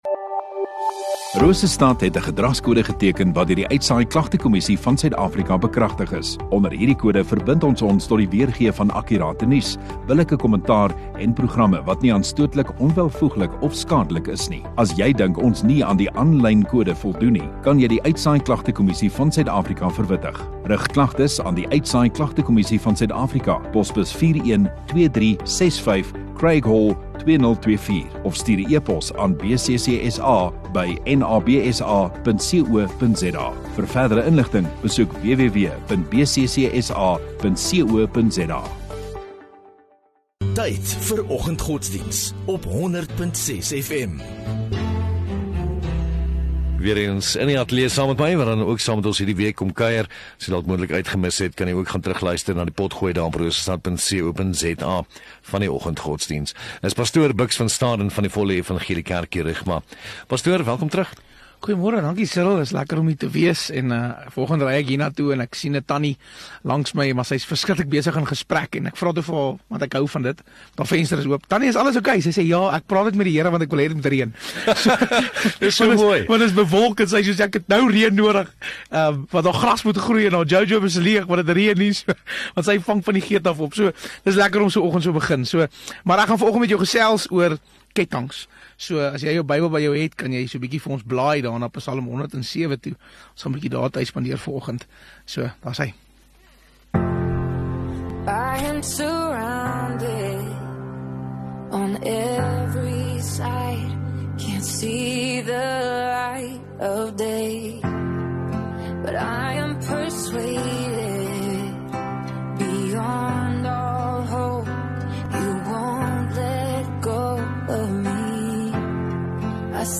10 Oct Dinsdag Oggenddiens